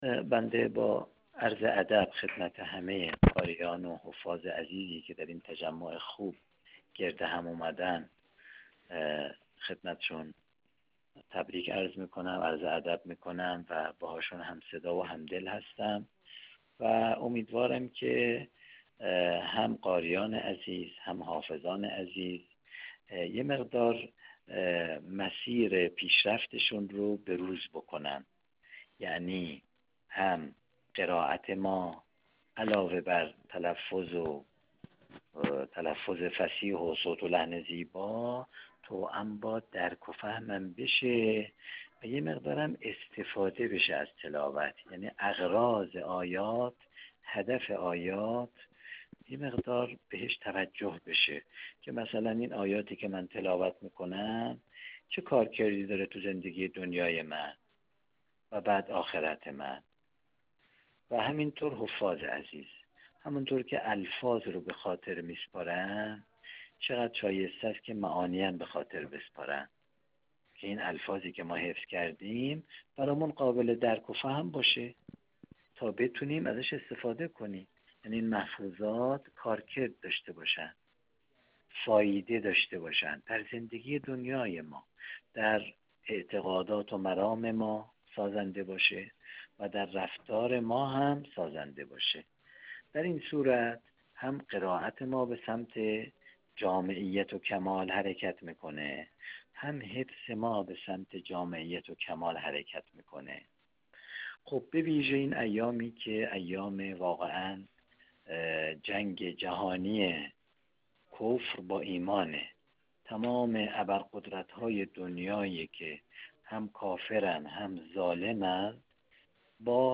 قاری و پیشکسوت قرآنی کشورمان